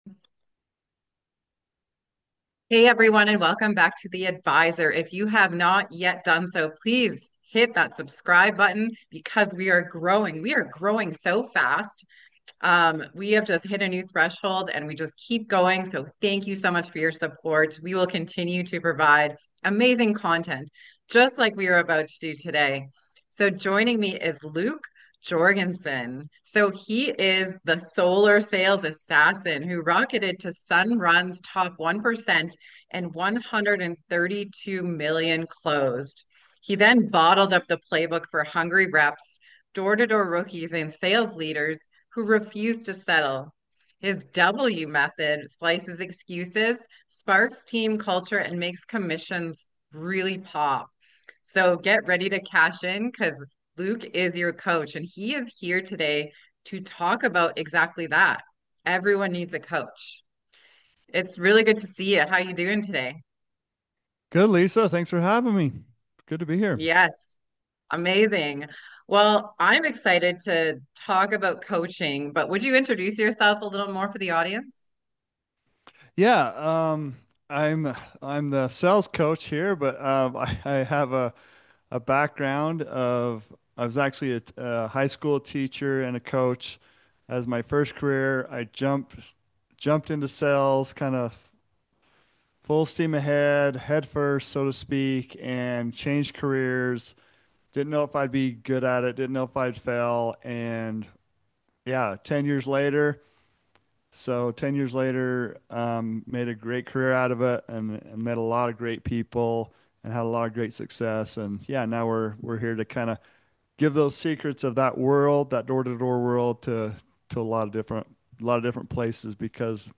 Love insightful and life-changing interviews?